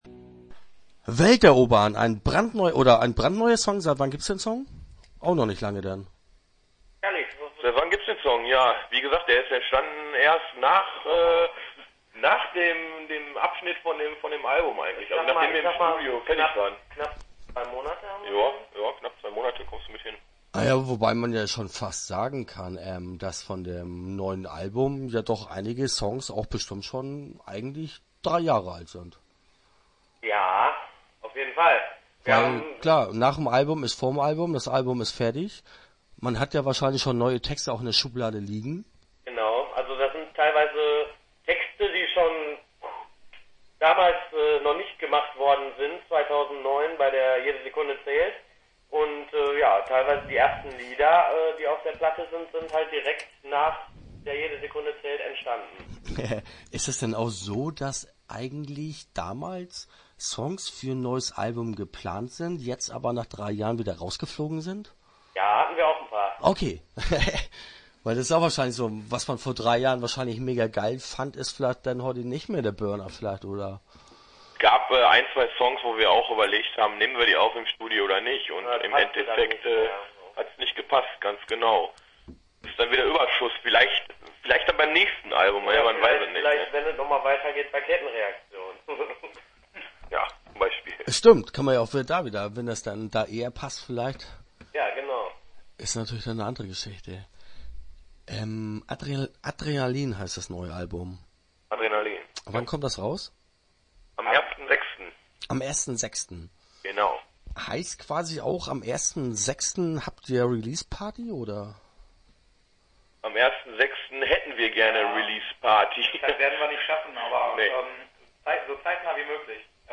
Interview Teil 1 (7:03)